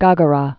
(gägə-rä) or Gha·ghra (gägrə, -grä) also Gog·ra (gŏgrə, -rä)